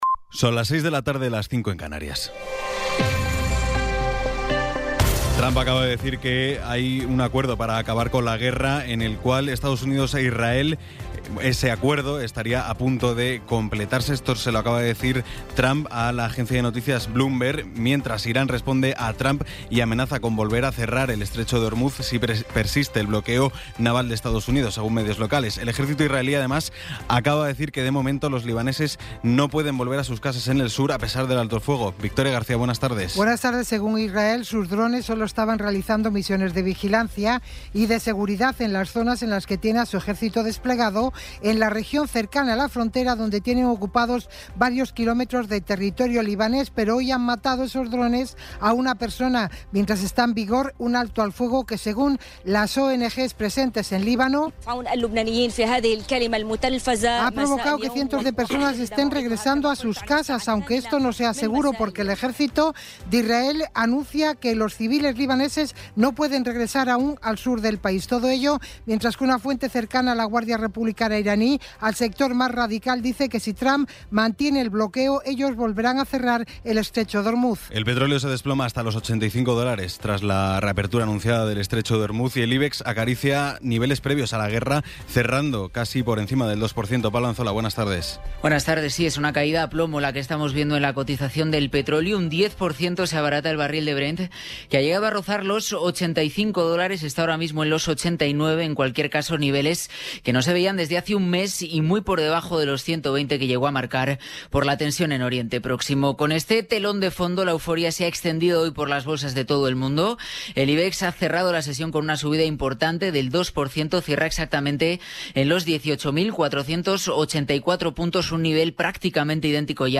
Resumen informativo con las noticias más destacadas del 17 de abril de 2026 a las seis de la tarde.